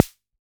Index of /musicradar/retro-drum-machine-samples/Drums Hits/Raw
RDM_Raw_SR88-Snr.wav